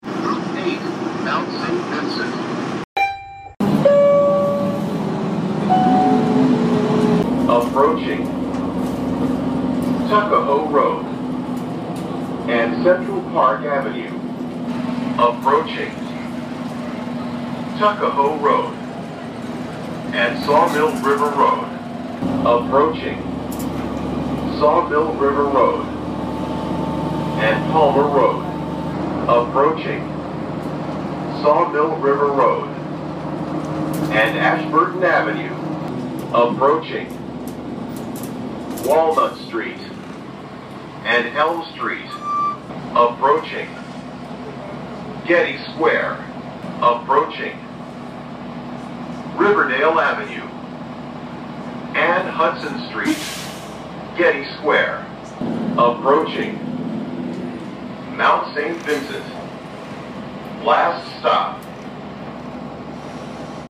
Announcements to Mount Saint Vincent